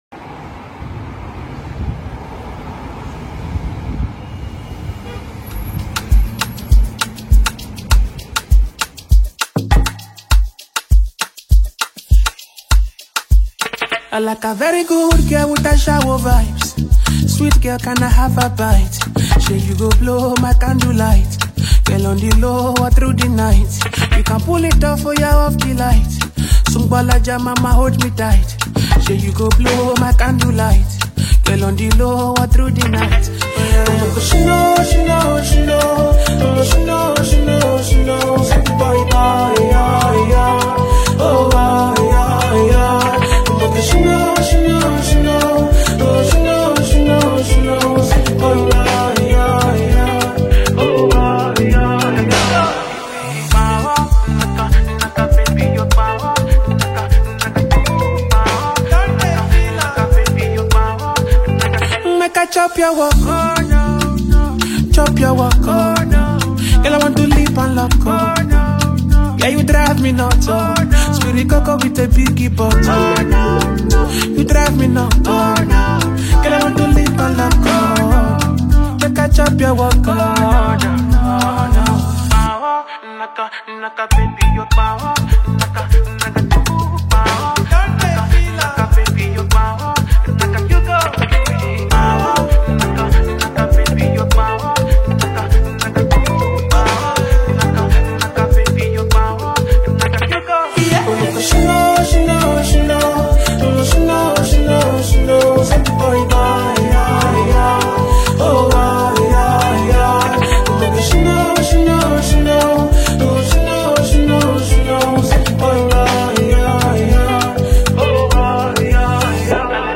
Rising afro-pop
the mid-rhythm resonant tune